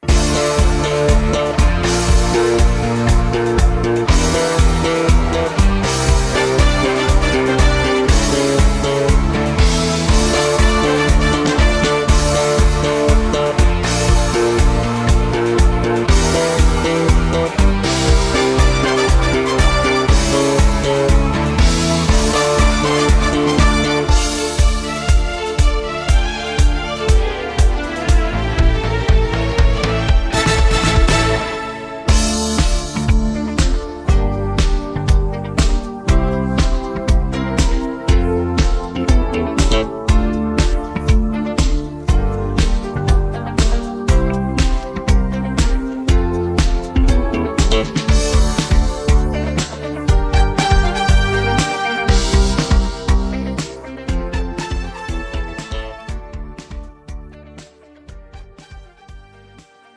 음정 -1키
장르 가요 구분